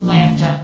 Yogstation/sound/vox_fem/lambda.ogg
* AI VOX file updates